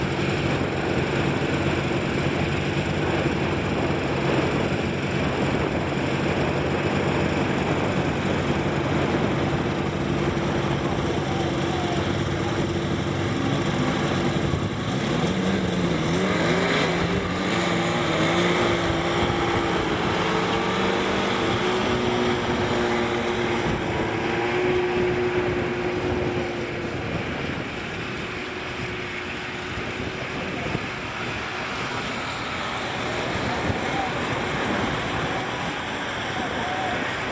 努纳维克 " 雪地摩托组嘎嘎地经过
描述：雪地摩托车队快速过关
Tag: 雪上汽车 rattly